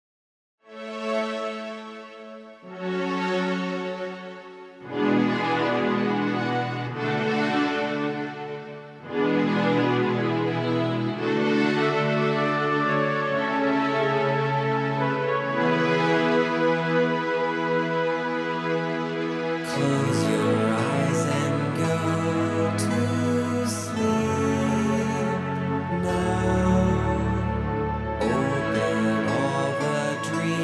Home > Lullabies